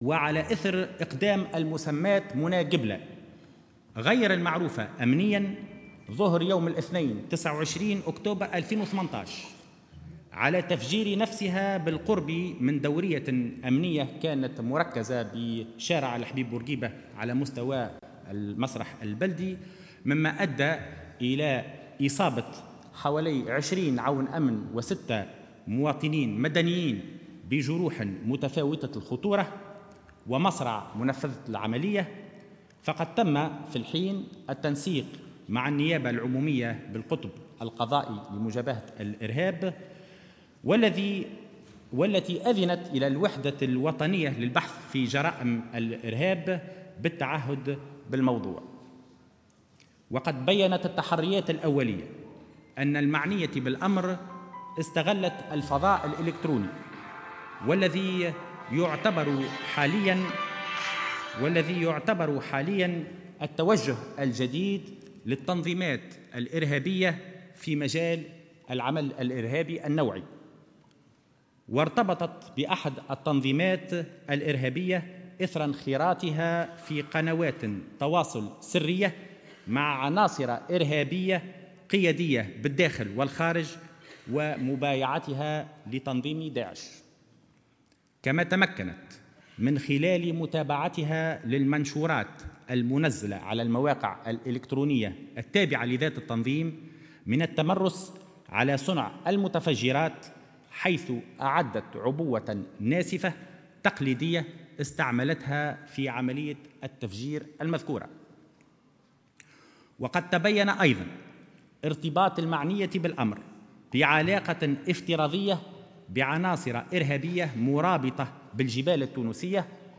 وأضاف الوزير خلال جلسة مساءلة له رفقة وزير العدل اليوم الاثنين بمجلس نواب الشعب حول 'الغرفة السوداء'، أنها انخرطت في قنوات تواصل سري مع عناصر إرهابية بالداخل والخارج مبايعة لـ'داعش' الإرهابي، ثم تدربت على صنع المتفجرات والعبوات الناسفة افتراضيا من طرف عناصر إرهابية.